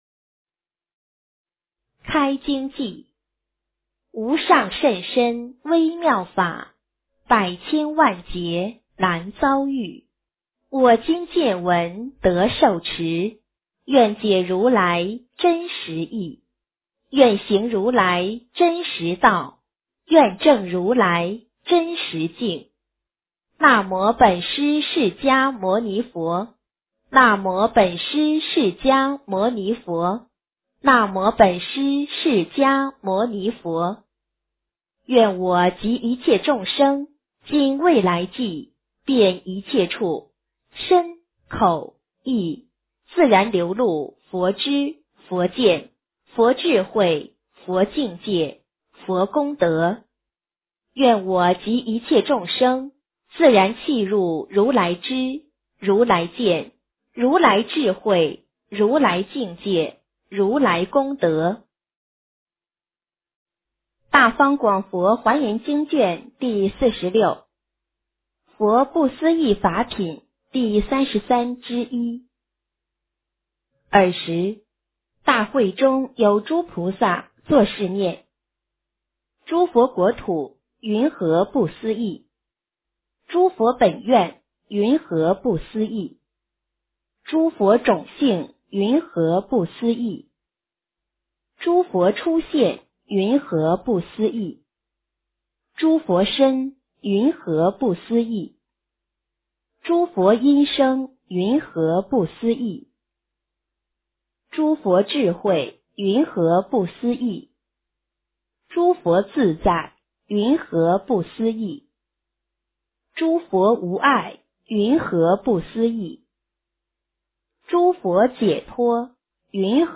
华严经46 - 诵经 - 云佛论坛